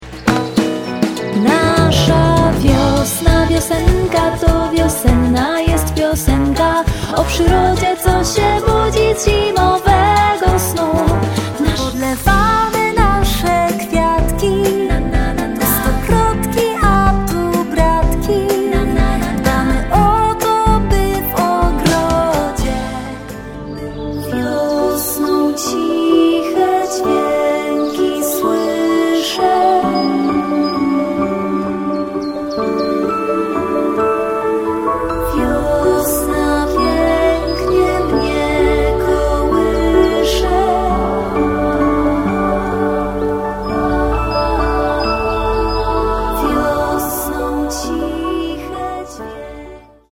Pakiet piosenek na Dzień Wiosny 🌸 | Zestaw Muzyczny 9w1